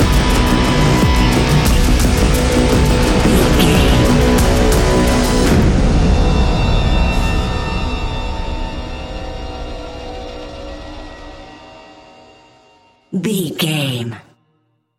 Thriller
Ionian/Major
B♭
dark ambient
EBM
drone
synths